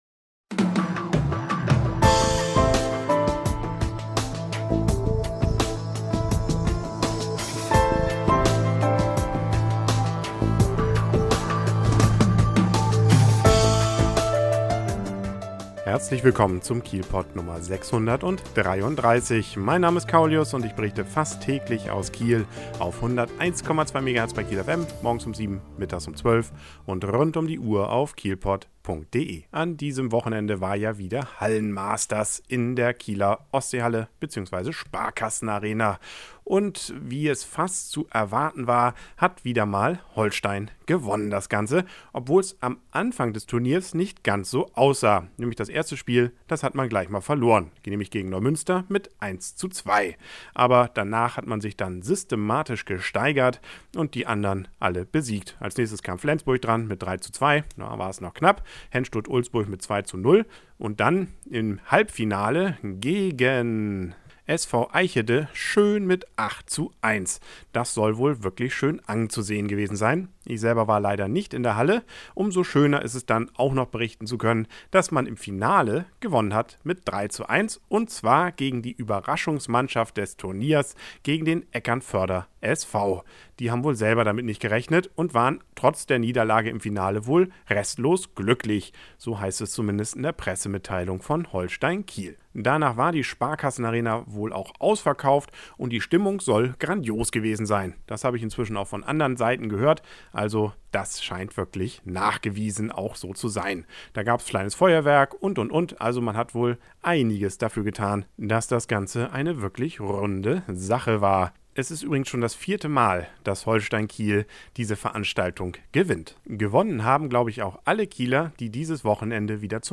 Holstein Kiel gewinnt zum vierten Mal das Hallenmasters in der Sparkassen Arena. Außerdem war wieder viel los auf den Kieler Rodel-Bergen.